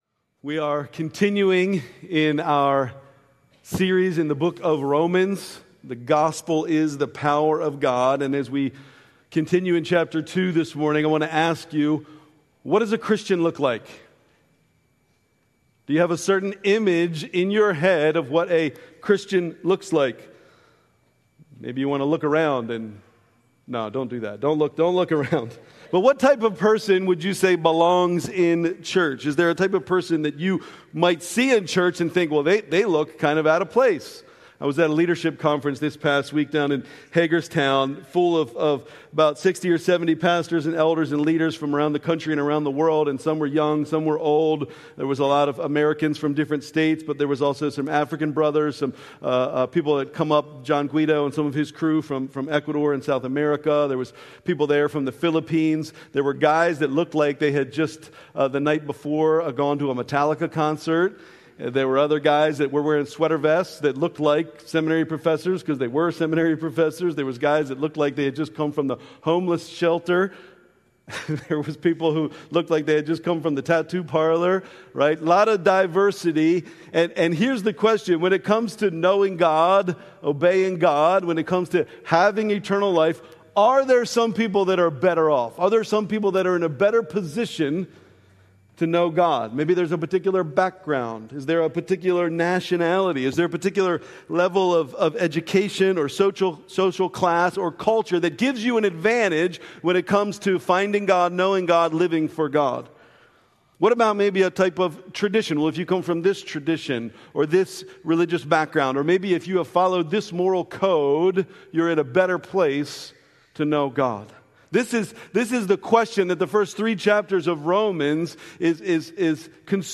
September 29, 2025 - Living Hope Church Worship Service